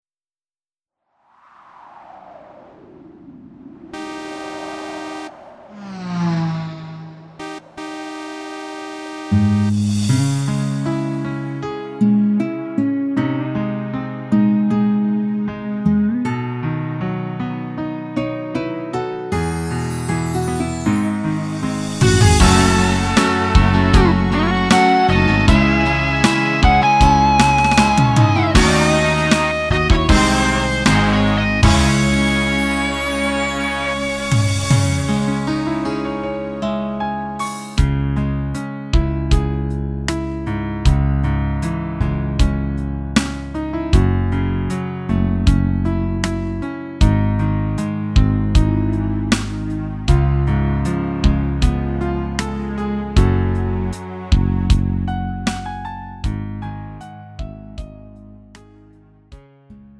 음정 -1키 3:24
장르 가요 구분